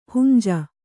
♪ hunja